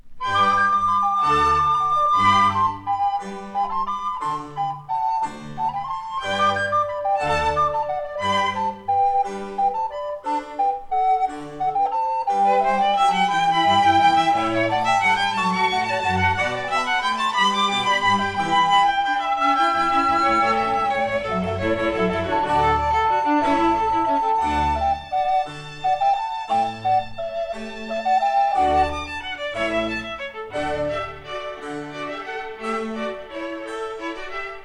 (recorders)
1960 stereo recording made by